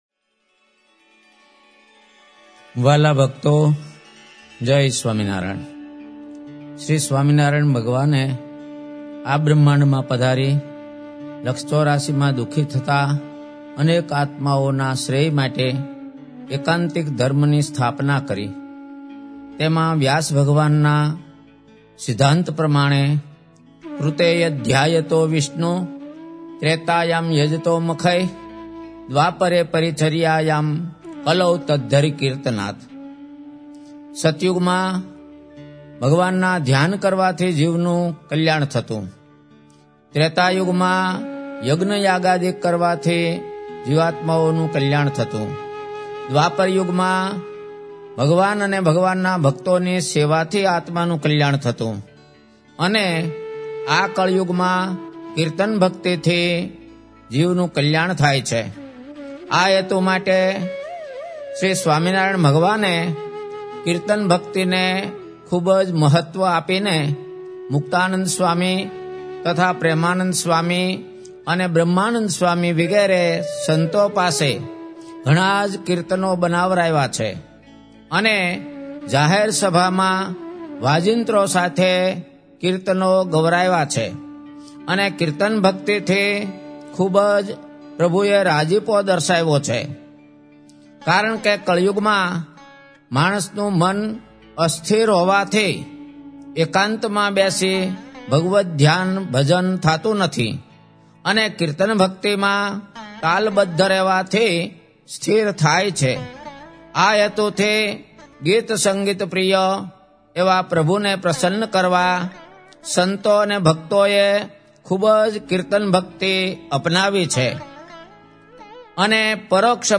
1 Speech (MvEa) 02:46